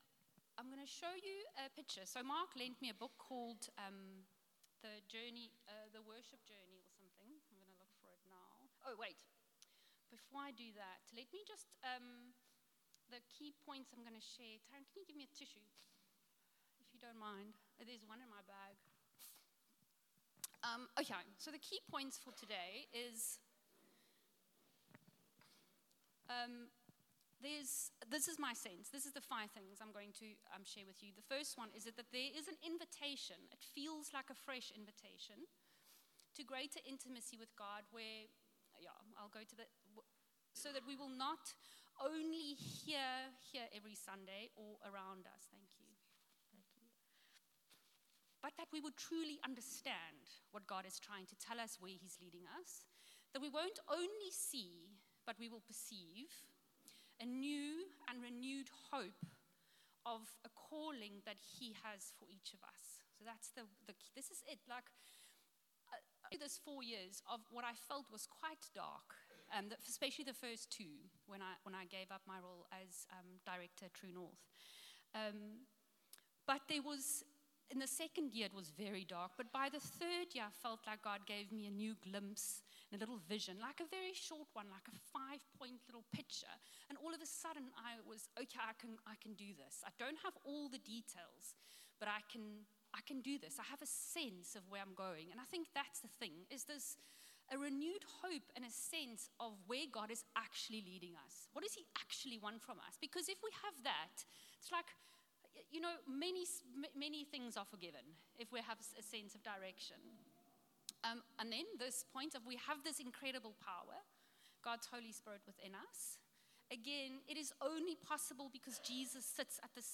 Sunday Service – 1 Dec
Sermons